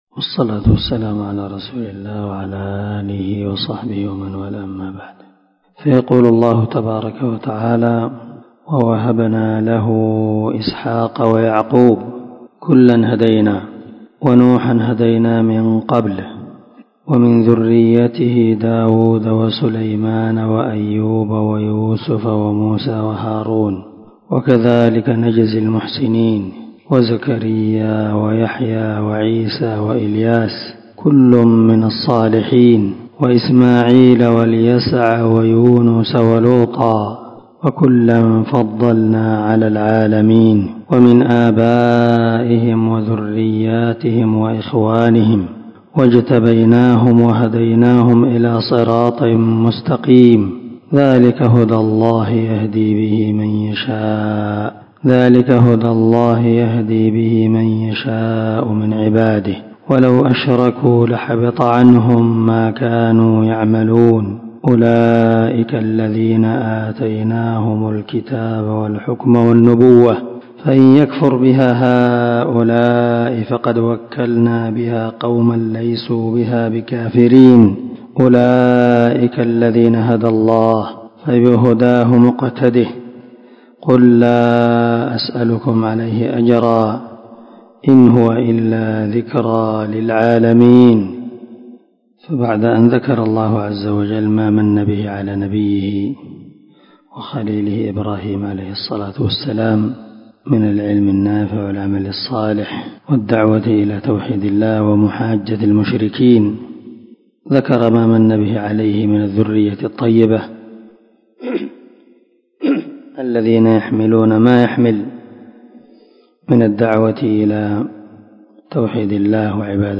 418الدرس 26 تفسير آية ( 84 – 90 ) من سورة الأنعام من تفسير القران الكريم مع قراءة لتفسير السعدي